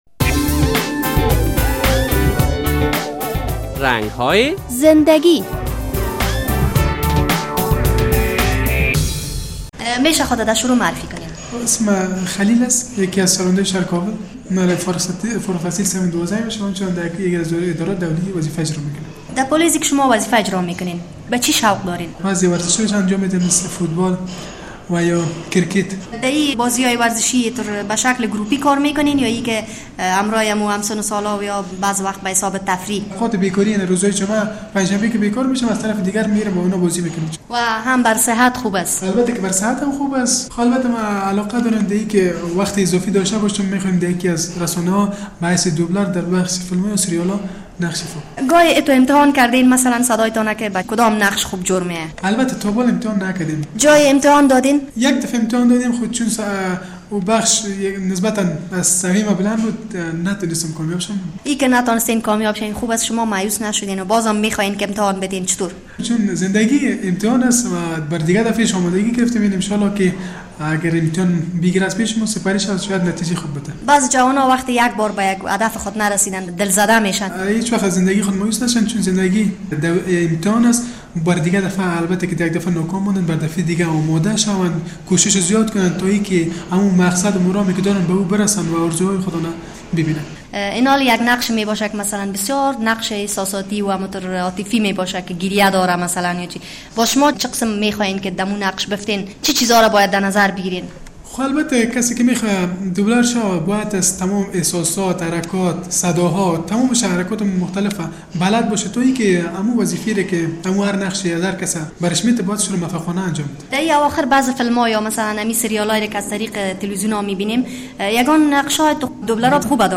وی در صحبتی که با خبرنگار رادیو آزادی داشت گفت به سپورت علاقمند است و در هنگام فراغت برخی ورزش های تفریحی را انجام می دهد.